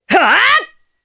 Morrah-Vox_Attack4_jp.wav